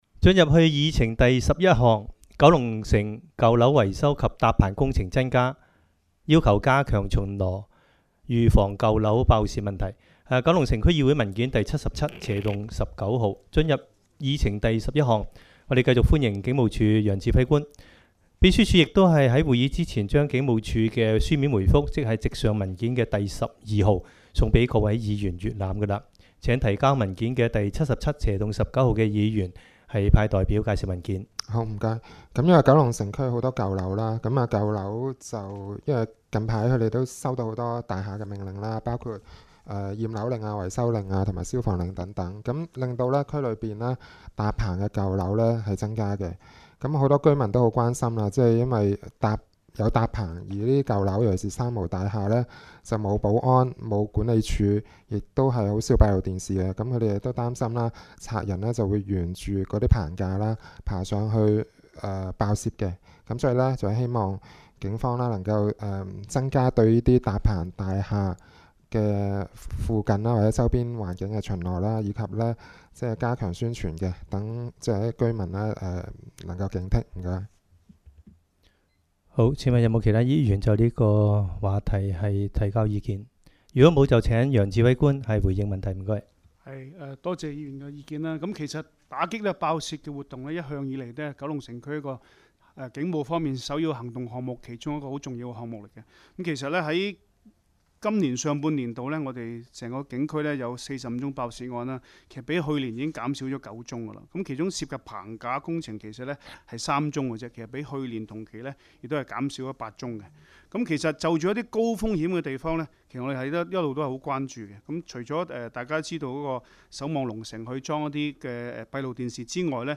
区议会大会的录音记录
九龙城区议会第二十二次会议
九龙城民政事务处会议室